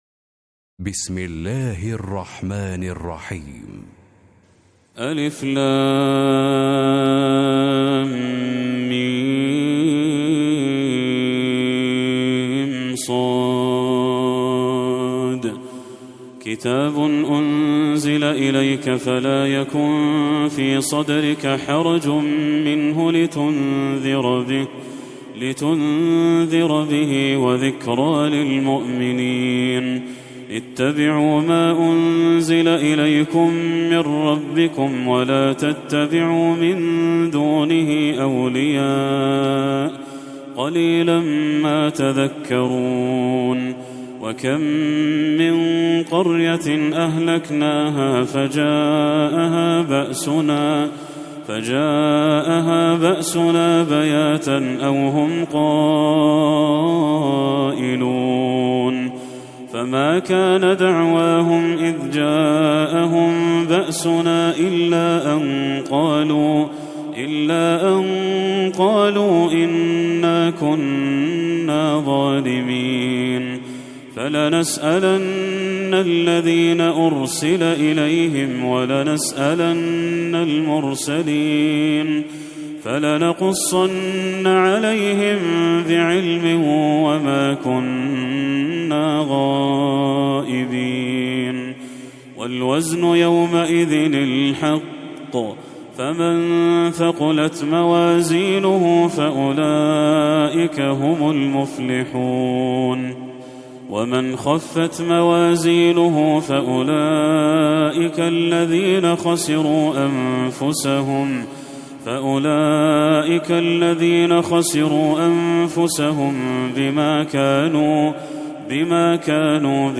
سورة الأعراف Surat Al-A'raf > المصحف المرتل